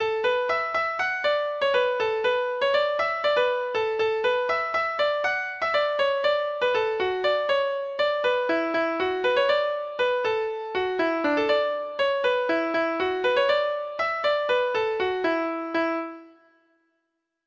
Folk Songs